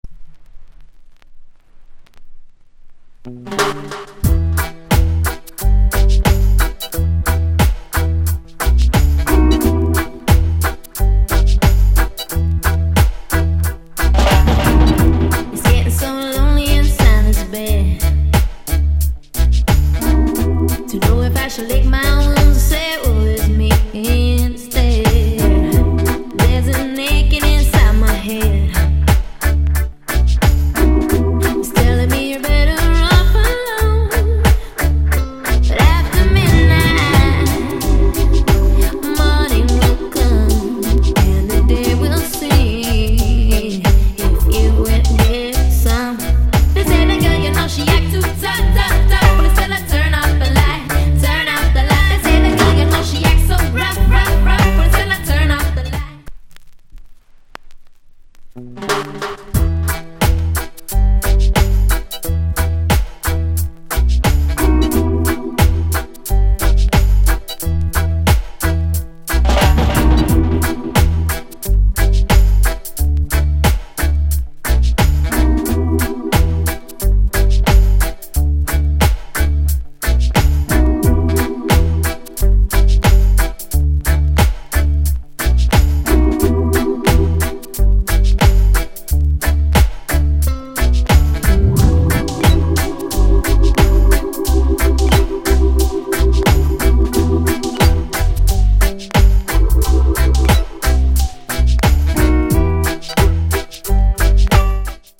甘い歌声が最高です！